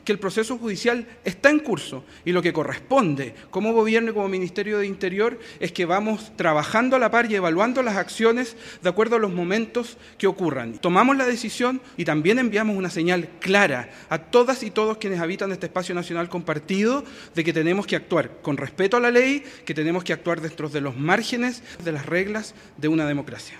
El subsecretario de Prevención del Delito, Eduardo Vergara, afirmó que con la iniciativa tomada por el Gobierno, se entregó un mensaje de respeto a las leyes de la democracia a quienes viven en el territorio.